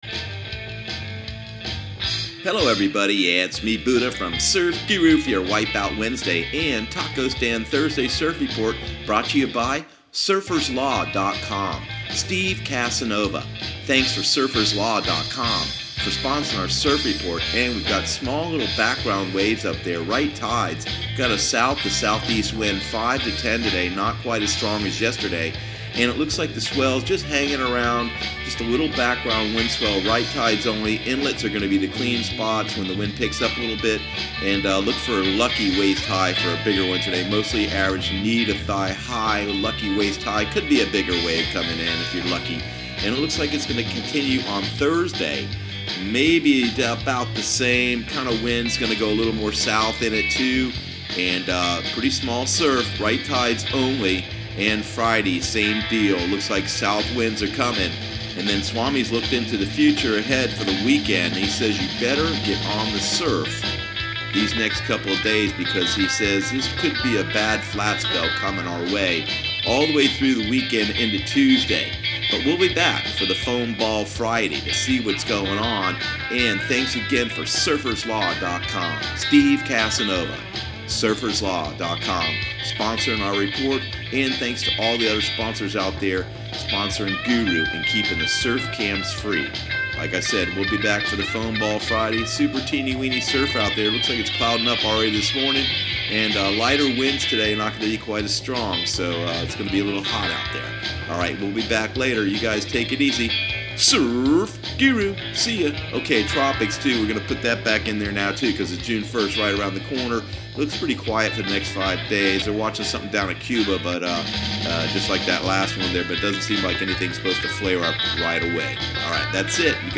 Surf Guru Surf Report and Forecast 05/30/2018 Audio surf report and surf forecast on May 30 for Central Florida and the Southeast.